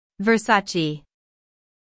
IPA: /vərˈsɑː.tʃeɪ/.
How to Pronounce Versace
Syllables: ver · SA · ce
Three syllables: "ver-SA-cheh".
versace-us.mp3